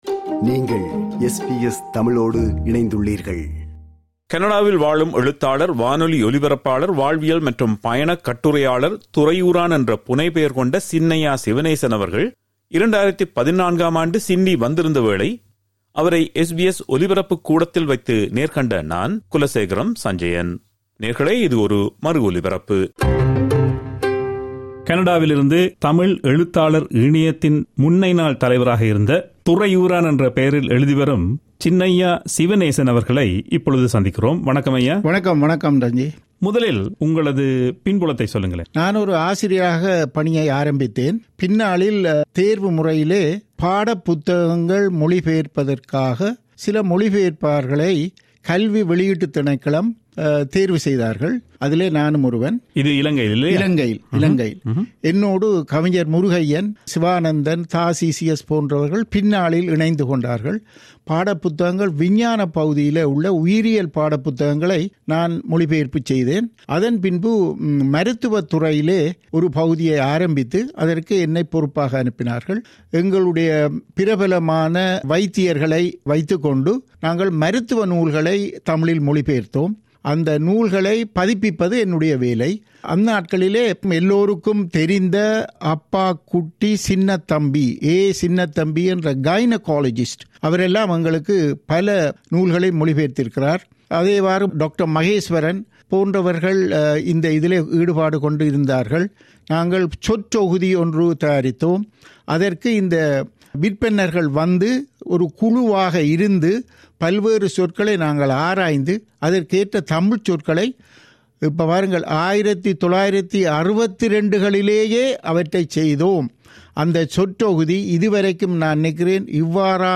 இது ஒரு மறு ஒலிபரப்பு